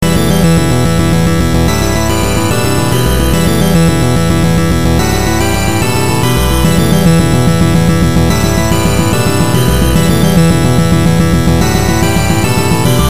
BPM=145 ショートバージョン フリー公開 素材曲として使用可能。
試聴用 MP3ファイル ループ再生になっておりますので、BGMなどの参考にしてください。
POINT ハットがあるとないとで大きな違いです。
BGM 暗い 早い